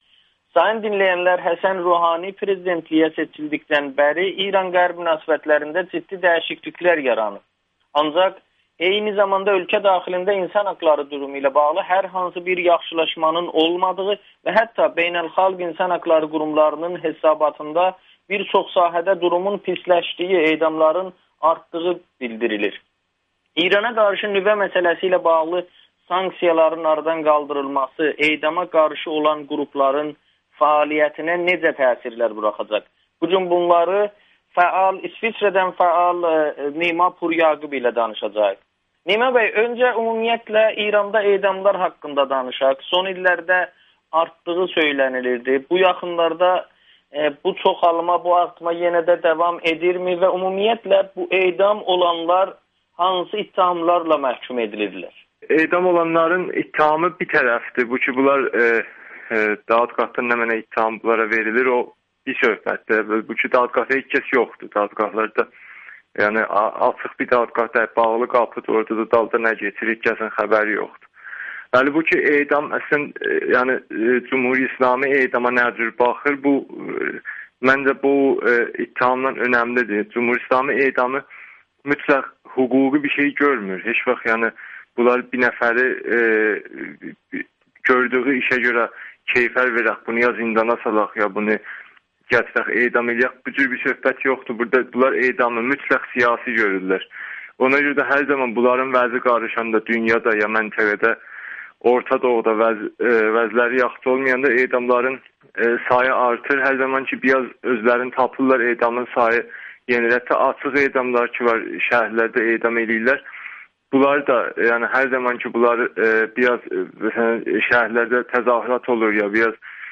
Avropa dövlətləri Ruhanidən edamlar haqda soruşmalıdır [Audio-Müsahibə]